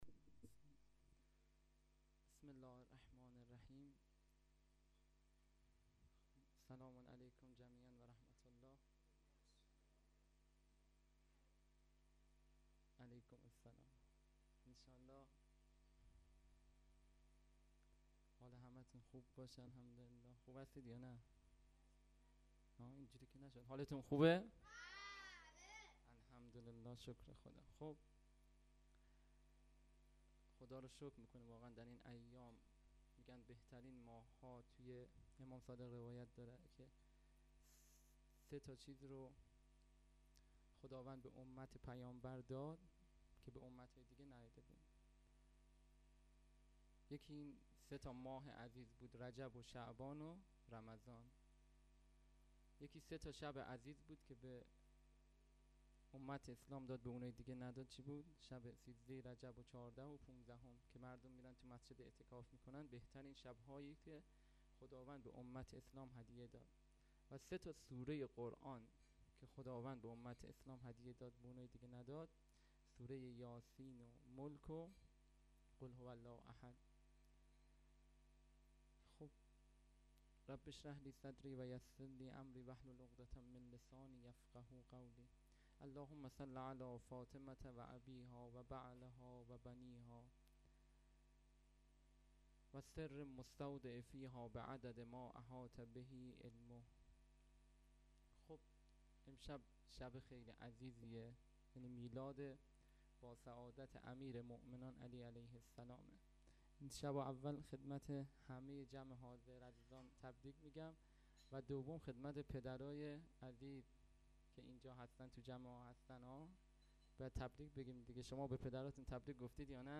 جشن میلاد حضرت علی93.mp3